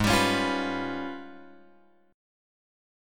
G#mM13 chord {4 x 5 6 6 7} chord